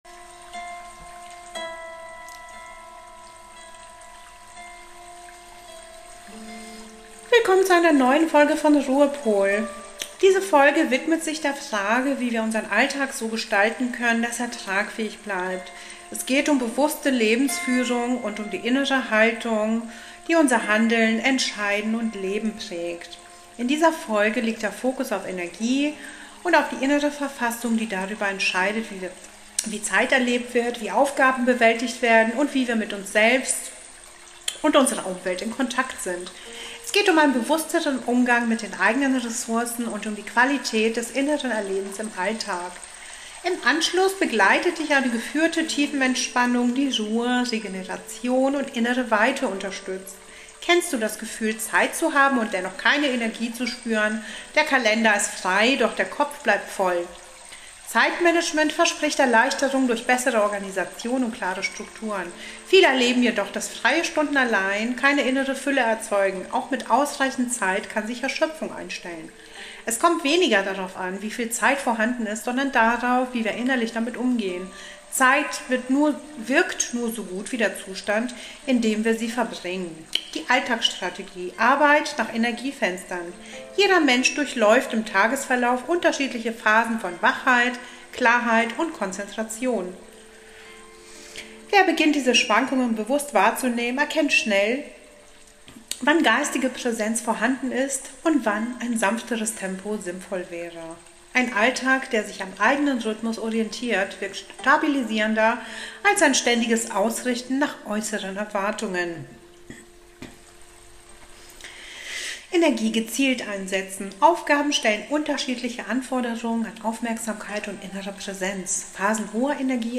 Im zweiten Teil begleitet dich eine geführte Tiefenentspannung zur Beruhigung des Nervensystems und zur Regeneration.